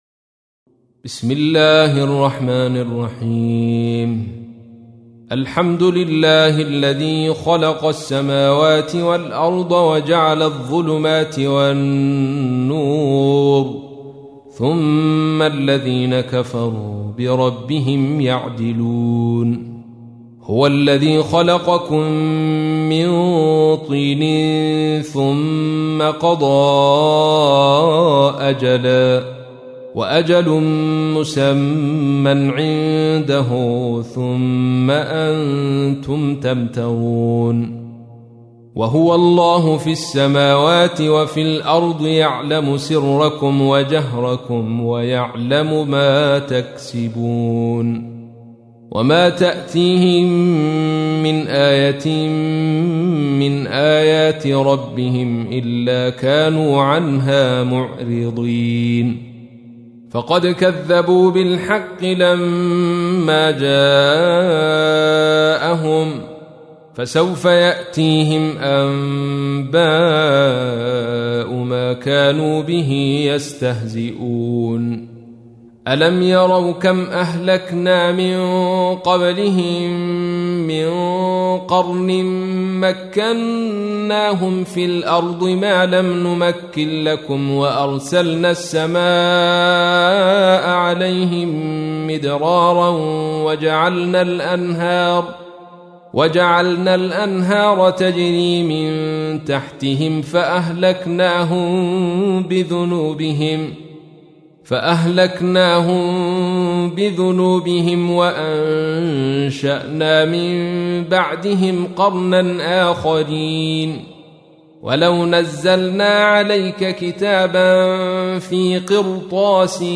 تحميل : 6. سورة الأنعام / القارئ عبد الرشيد صوفي / القرآن الكريم / موقع يا حسين